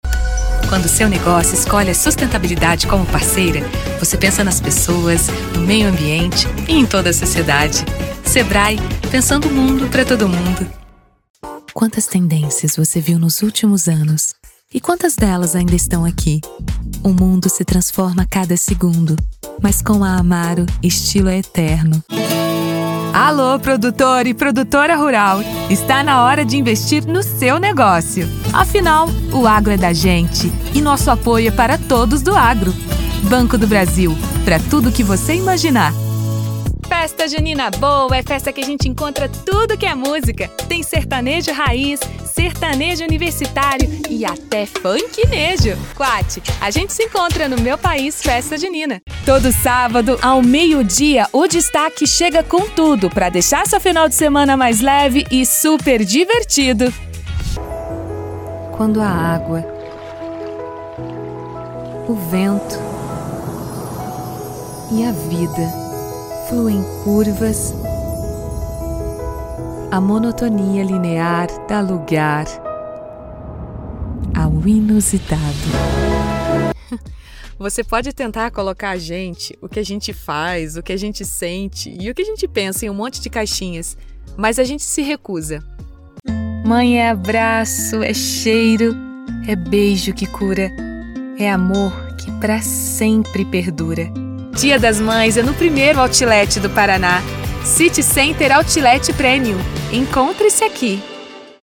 Brazilian Portuguese voiceover Brazilian Portuguese emotional voice Brazilian Portuguese dubbing
Sprechprobe: Werbung (Muttersprache):